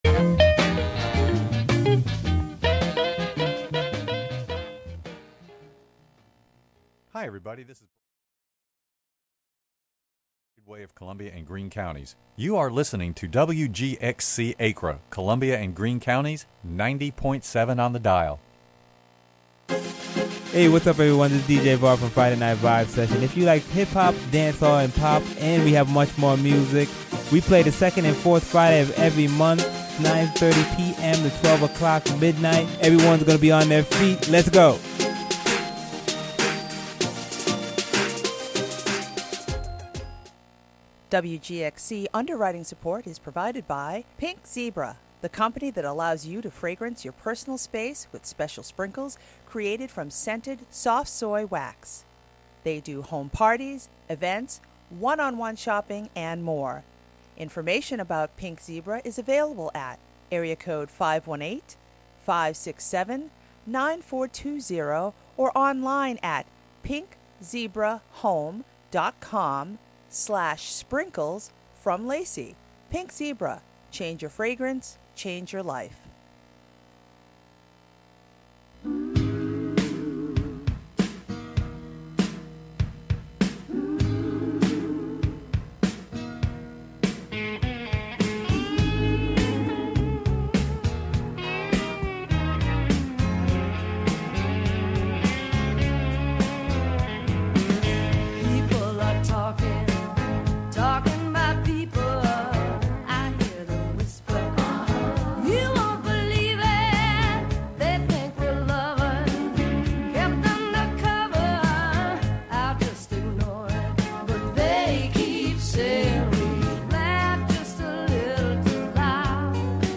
First-term Congressman, Republican John Faso will be the guest for the hour. He will discuss his record, so far, his campaign, and more. The show features interviews and discussion with political figures and newsmakers on a range of topics of importance to Columbia County, N.Y., and beyond.